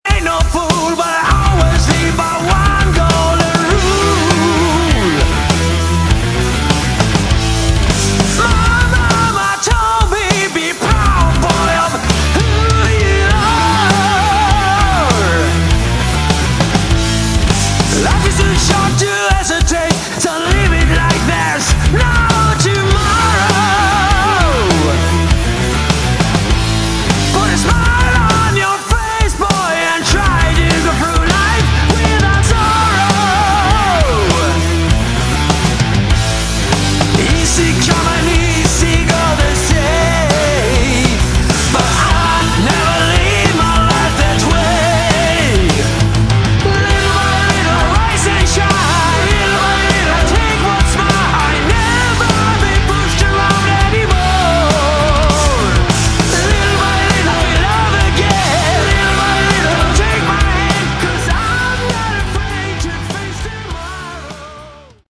Category: Hard Rock
bass
guitar
vocals
keyboards
drums